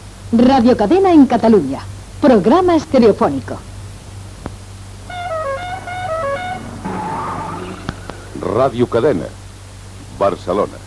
Indicatiu de l' emissora en estereofonia
FM